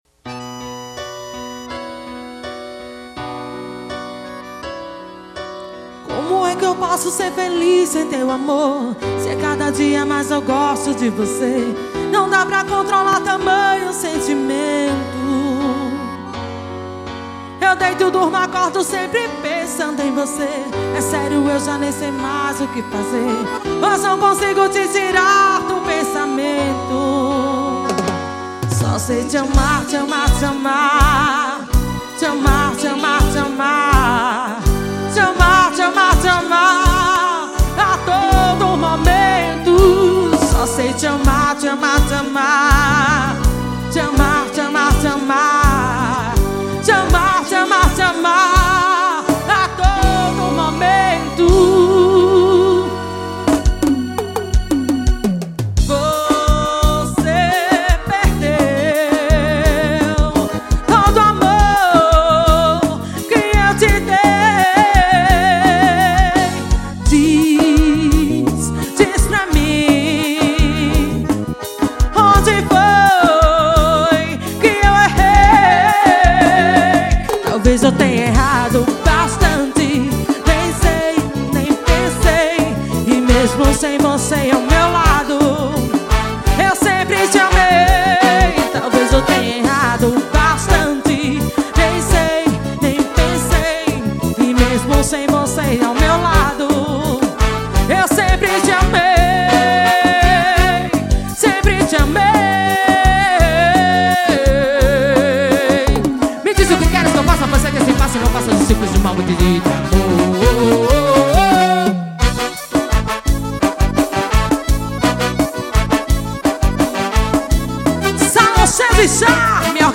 Composição: forro.